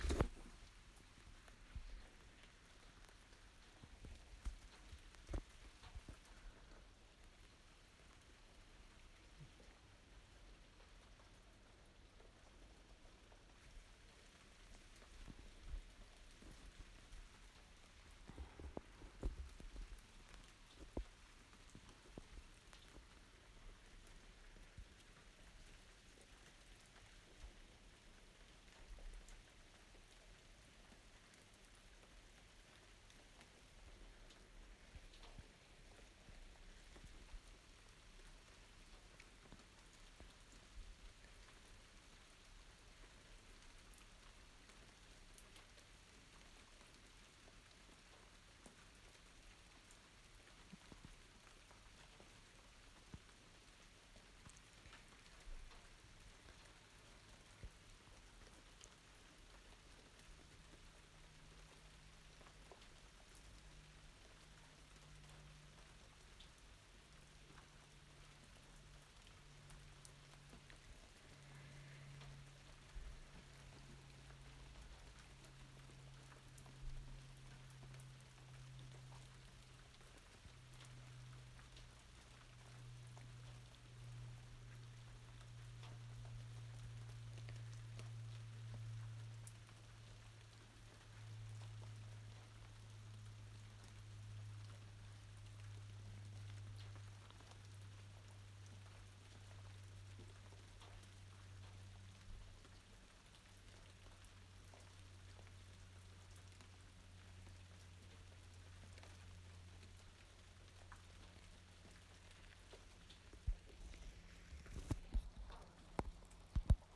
Evening rain, Sunday 8 January 2017